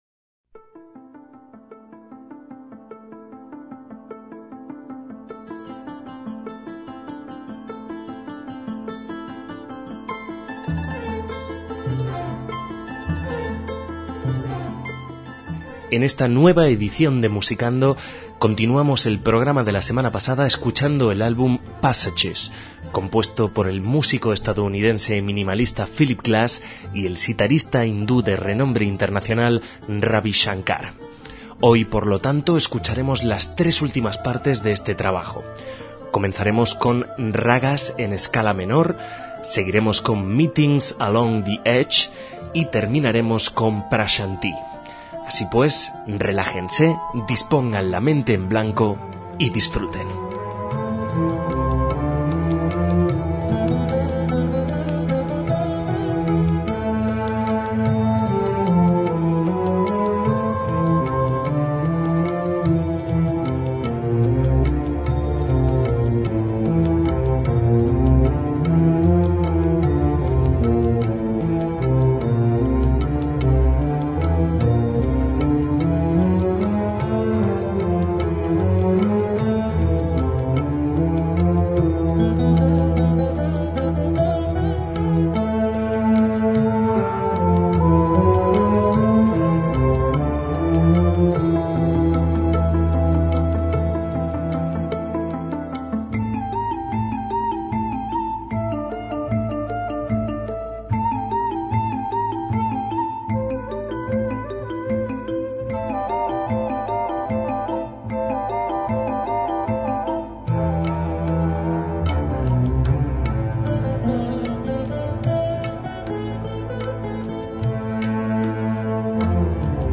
álbum de estudio de música de cámara